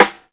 SNARE_WHEN_I_WAS_A_CHILD.wav